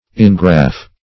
engraff - definition of engraff - synonyms, pronunciation, spelling from Free Dictionary Search Result for " engraff" : The Collaborative International Dictionary of English v.0.48: Engraff \En*graff"\, v. t. [See Ingraft .] To graft; to fix deeply.